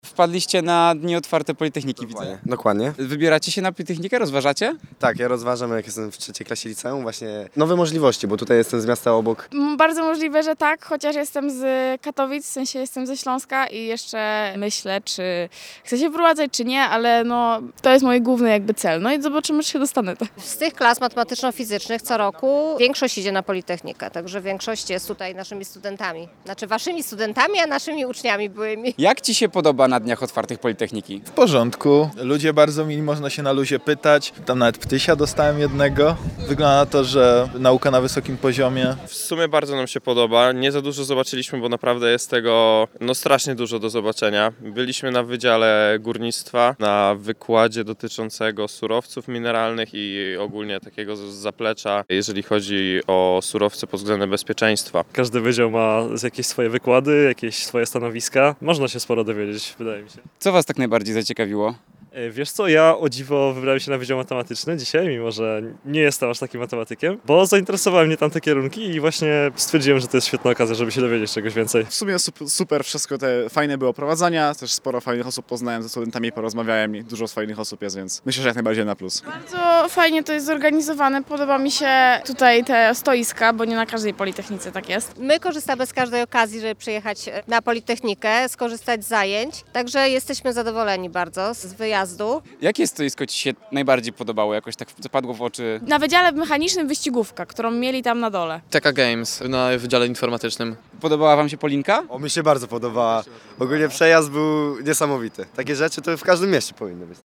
Dni otwarte Politechniki Wrocławskiej
sonda.mp3